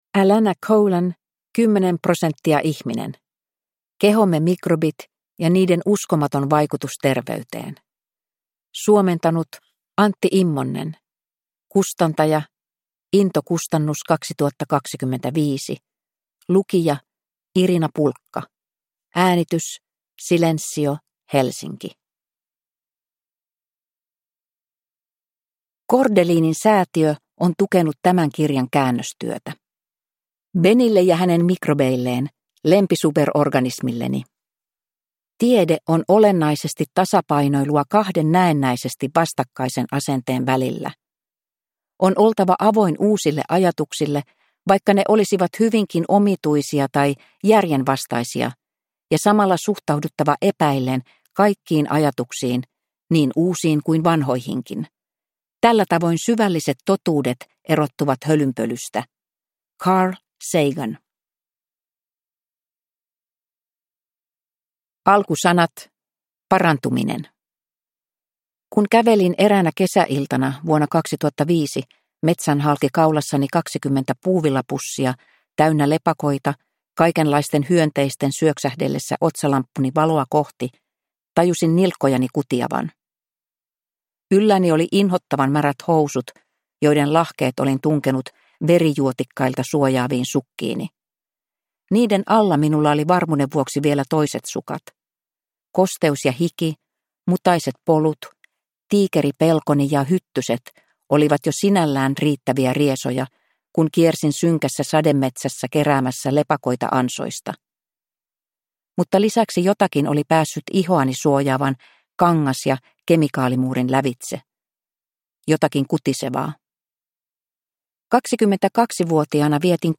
10 % ihminen – Ljudbok